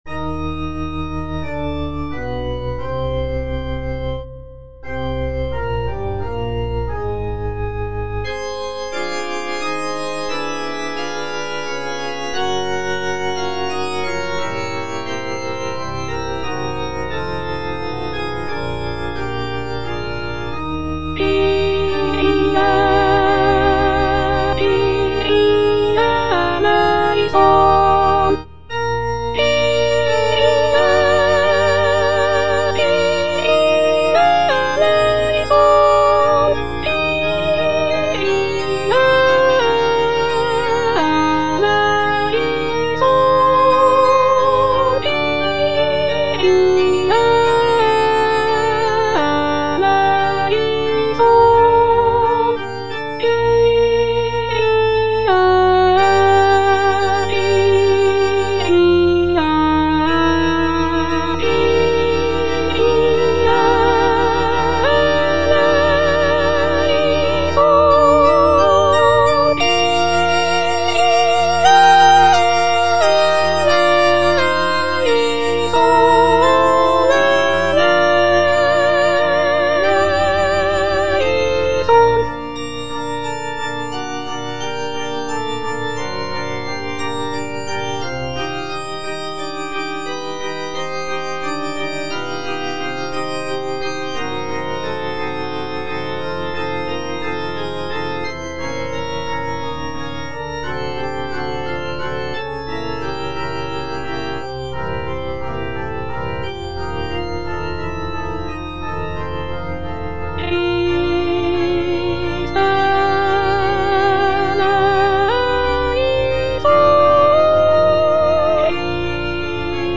The composition is a short and simple mass setting, featuring delicate melodies and lush harmonies.
G. FAURÉ, A. MESSAGER - MESSE DES PÊCHEURS DE VILLERVILLE Kyrie - Soprano (Emphasised voice and other voices) Ads stop: auto-stop